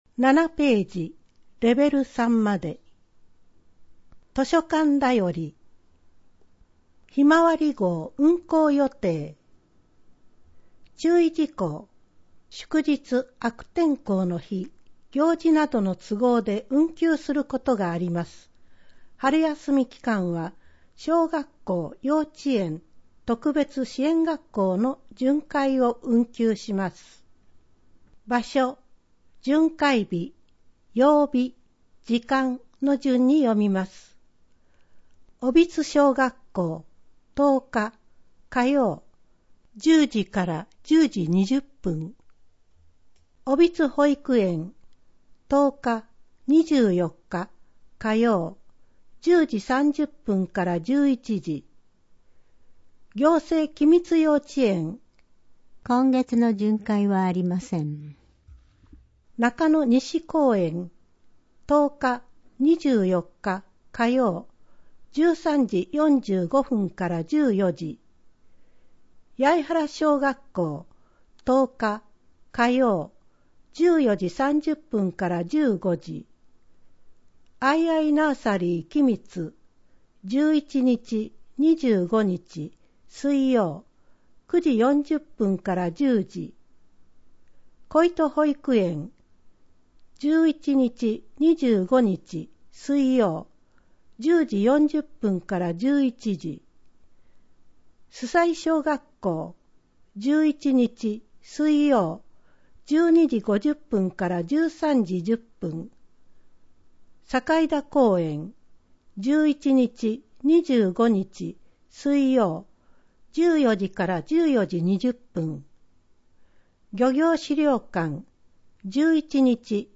声の広報とは、広報きみつを音訳し、CDに録音したものです。ボランティアグループ 音訳の会「さざなみ」 の協力により製作しています。